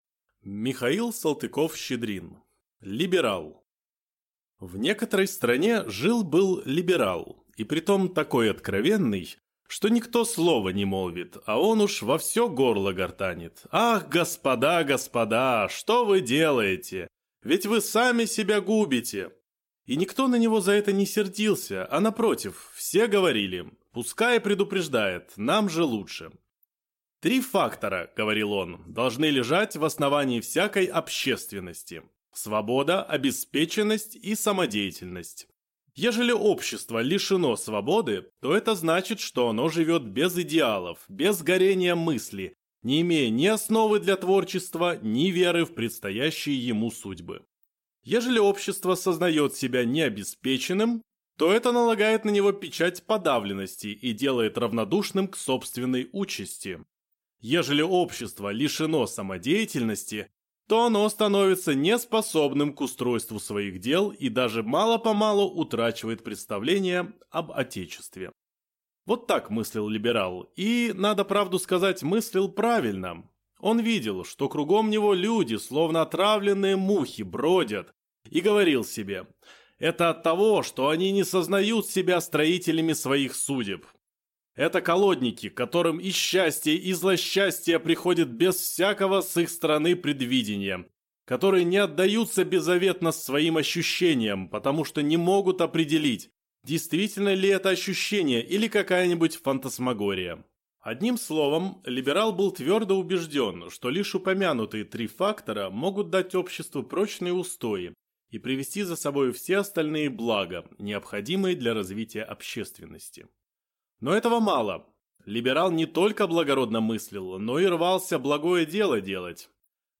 Аудиокнига Либерал | Библиотека аудиокниг